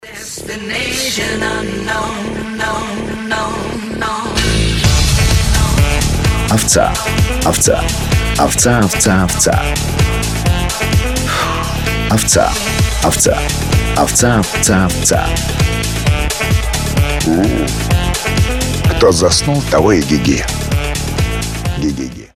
• Качество: 320, Stereo
Саксофон
house